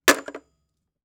Old Socotel S63 type telephone that we hang up.
Listen : Phone hung up #4 (1 s)